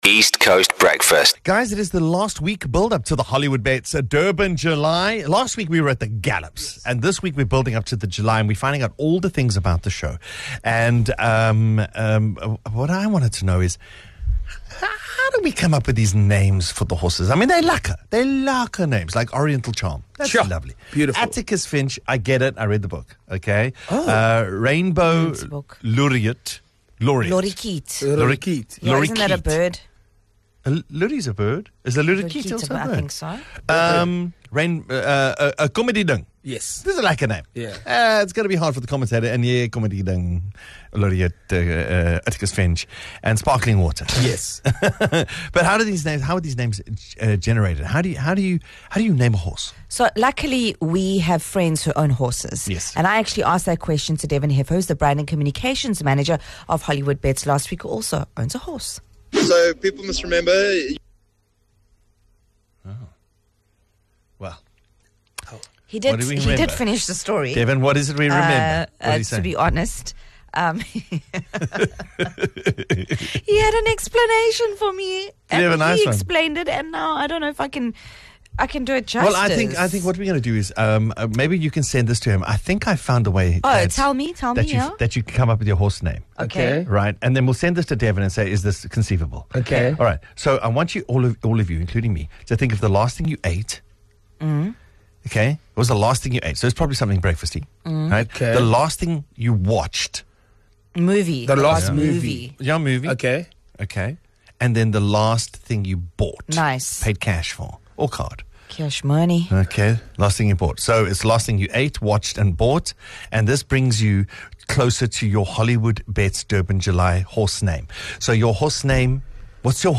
The East Coast Radio Breakfast Show is a fun, and hyperlocal radio show that will captivate and entertain you.